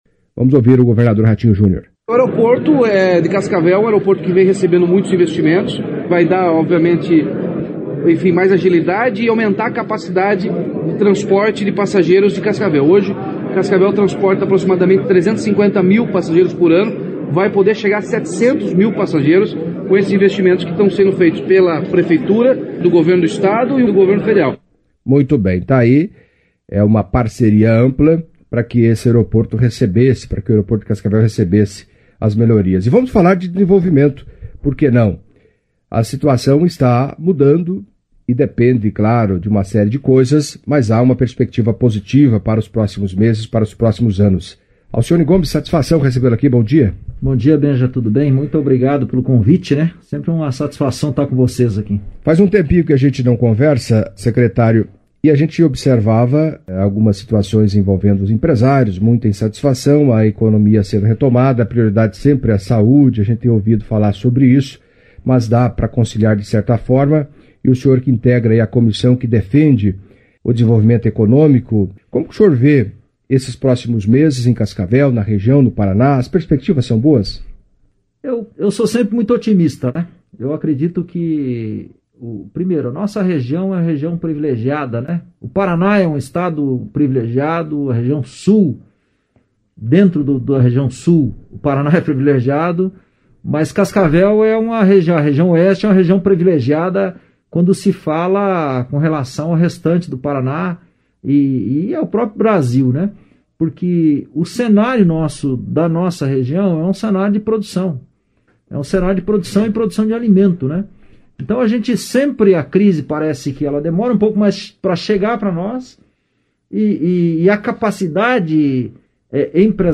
Secretário de Desenvolvimento Econômico de Cascavel, Alcione Gomes, em entrevista à CBN Cascavel, nesta segunda-feira(20), falou das obras do Aeroporto Municipal, reabertura gradual das atividades econômicas e da importância de uma união de forças em defesa de Cascavel.